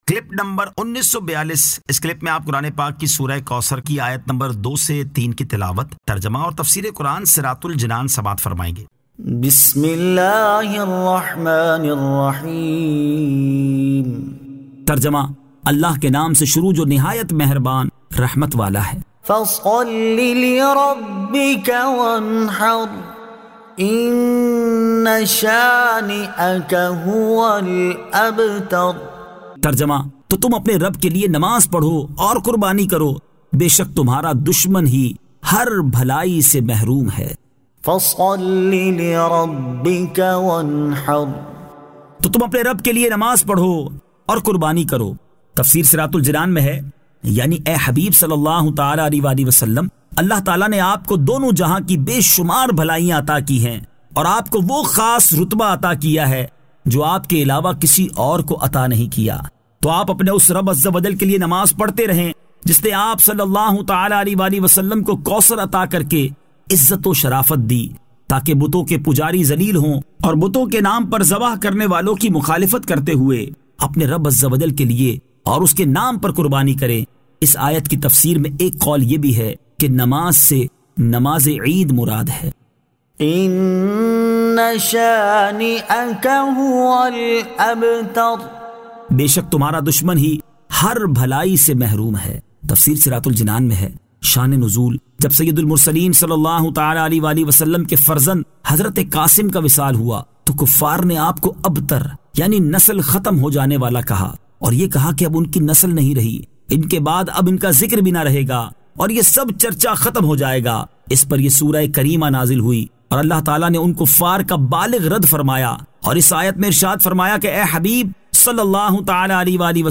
Surah Al-Kawthar 02 To 03 Tilawat , Tarjama , Tafseer